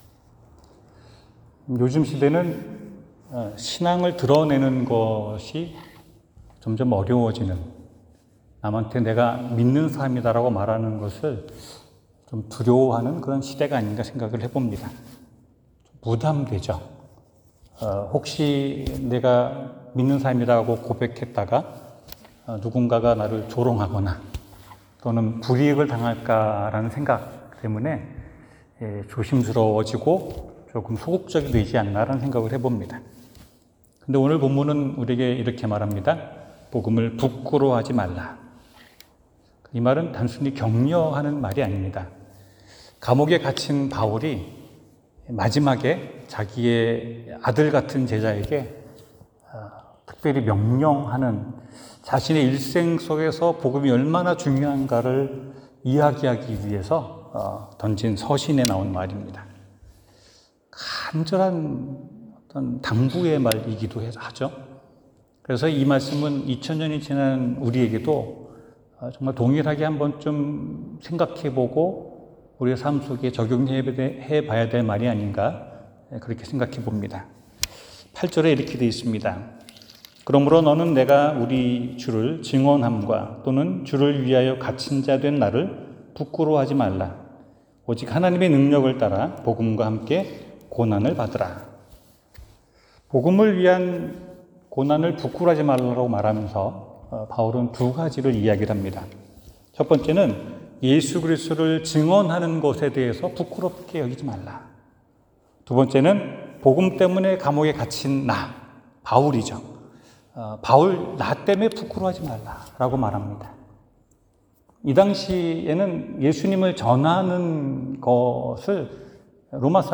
복음을 부끄러워 하지 말라 성경: 디모데후서 1:8-18 설교